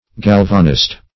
Galvanist \Gal"va*nist\, n. One versed in galvanism.